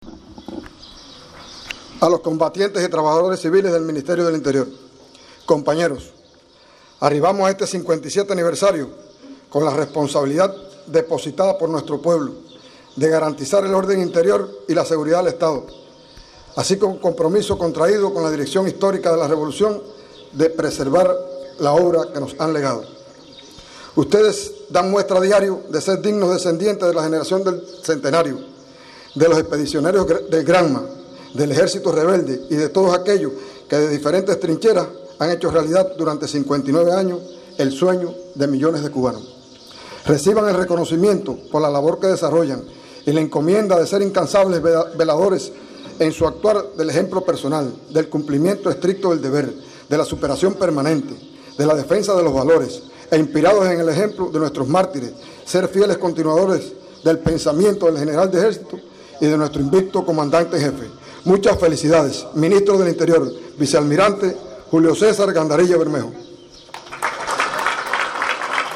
Un emotivo acto de ascenso y condecoraciones en el museo Ñico López, de Bayamo, devino la celebración en Granma del aniversario 57 de la constitución del Ministerio del Interior, coraza de acero y escudo de la Patria.